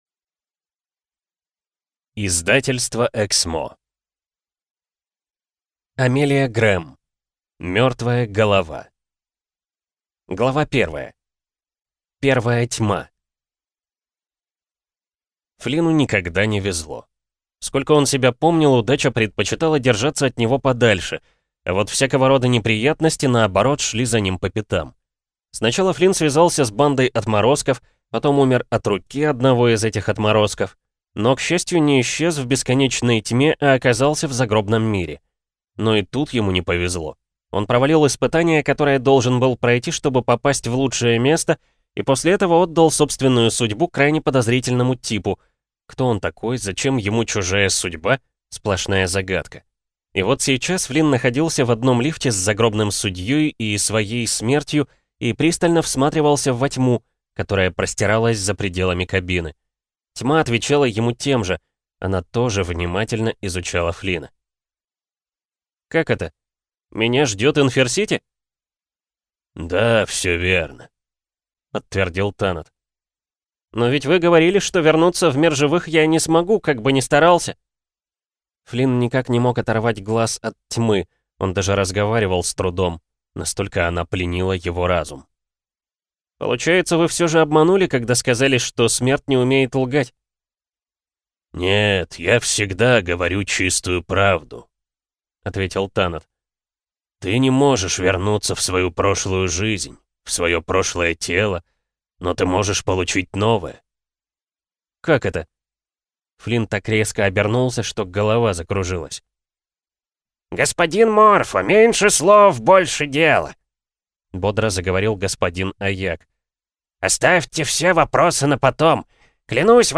Аудиокнига Мертвая голова | Библиотека аудиокниг